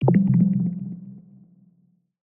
UI_Ding_Bronze.ogg